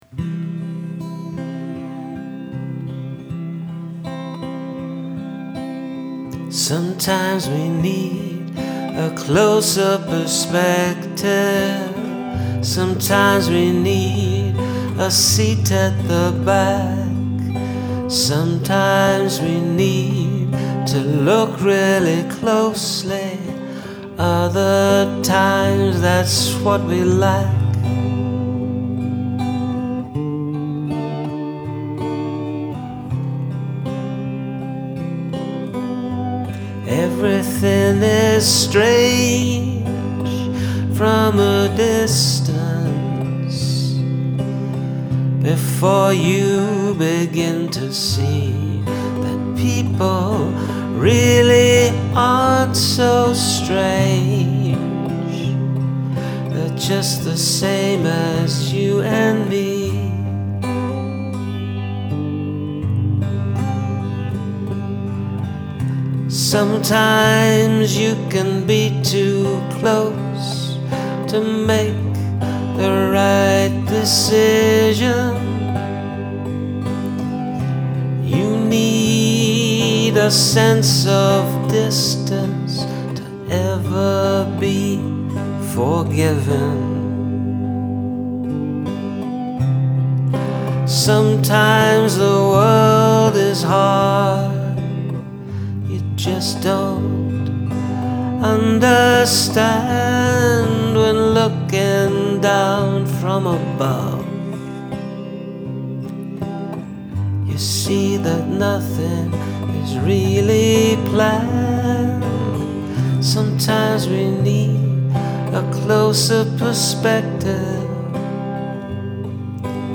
Cool sound - I like the lushness of the music.
This has an REM vibe, very nice.